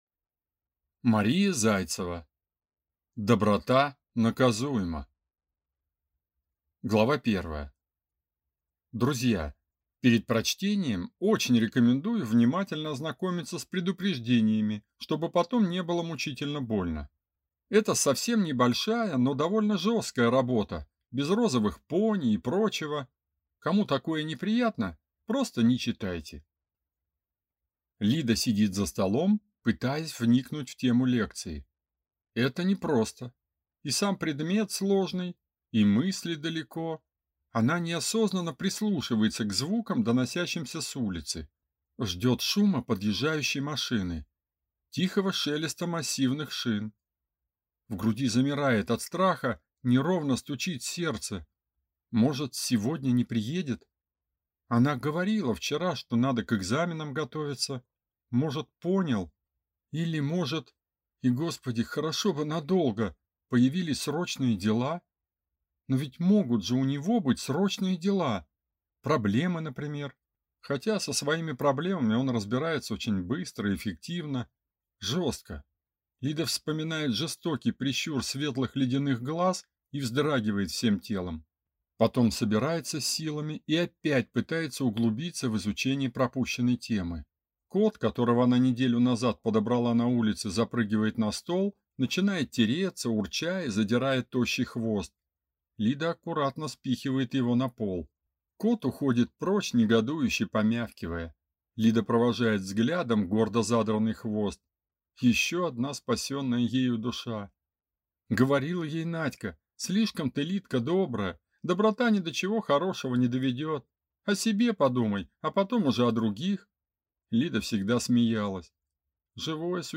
Аудиокнига Доброта наказуема | Библиотека аудиокниг